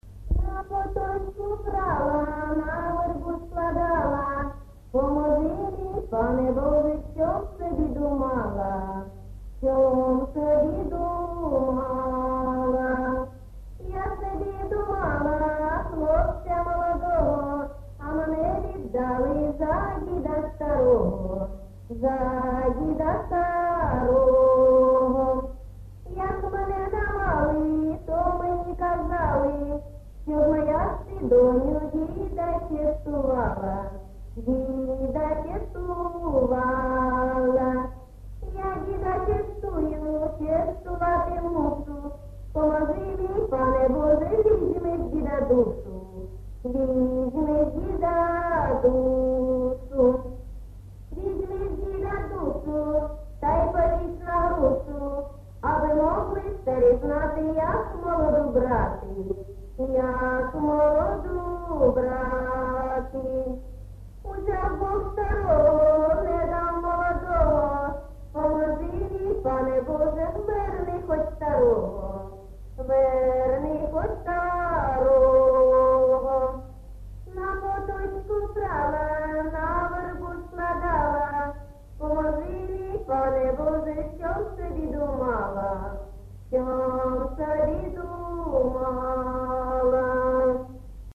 ЖанрПісні з особистого та родинного життя, Жартівливі, Пісні літературного походження
Місце записус. Золотарівка, Сіверськодонецький район, Луганська обл., Україна, Слобожанщина